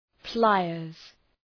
Προφορά
{‘plaıərz}